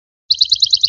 Category: Bird Ringtones